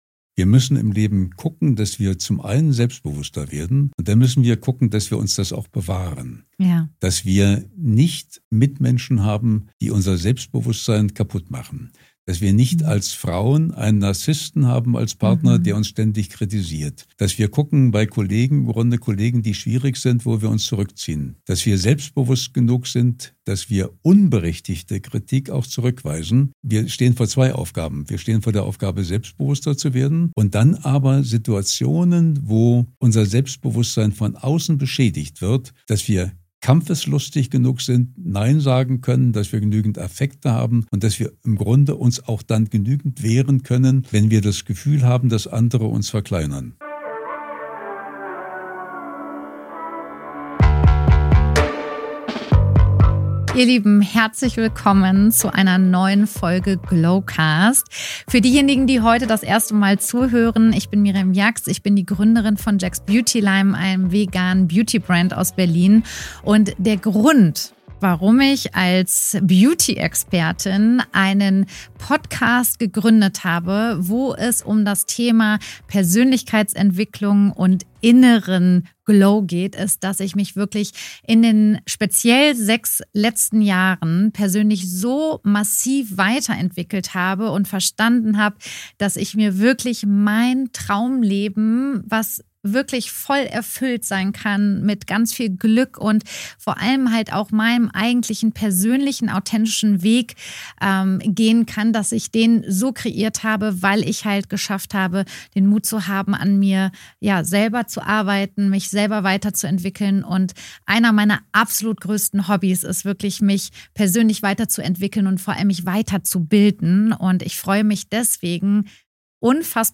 Cold Open